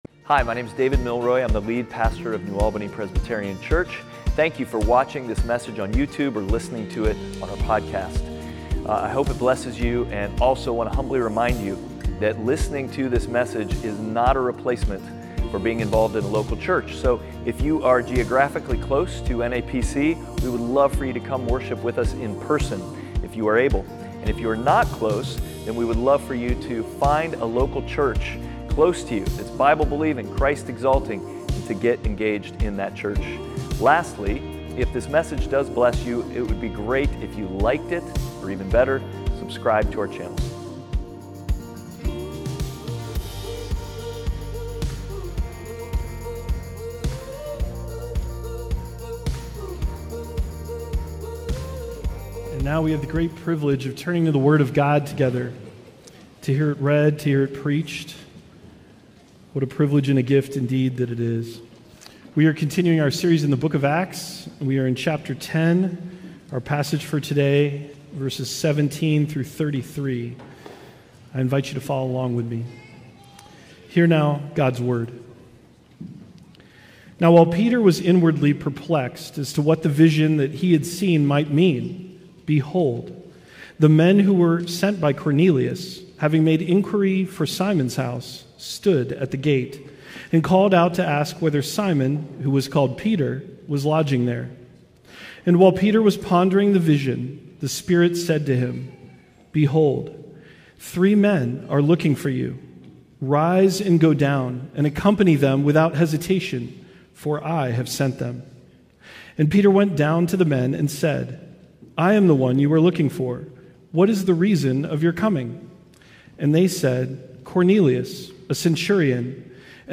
Outward Passage: Acts 10:17-33 Service Type: Sunday Worship « Outward